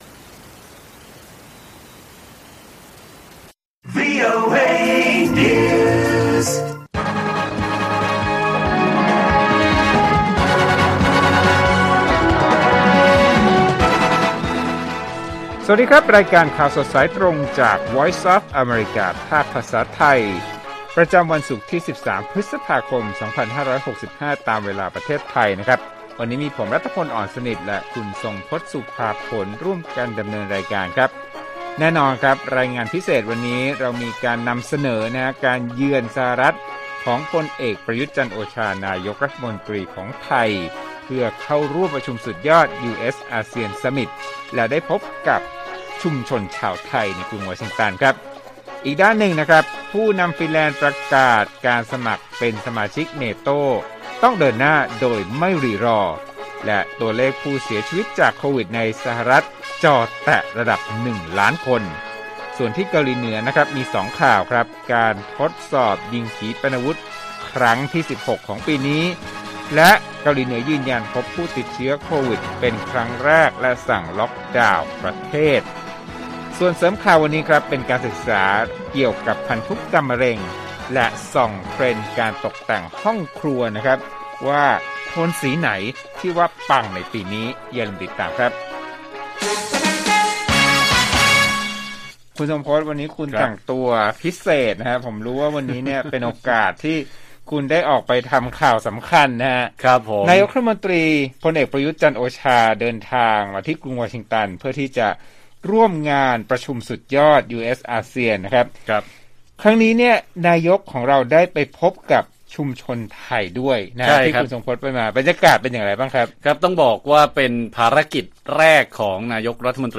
ข่าวสดสายตรงจากวีโอเอไทย 8:30–9:00 น. วันที่ 13 พ.ค. 65